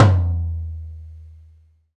TOM XTOMLO0G.wav